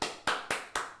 clapping.ogg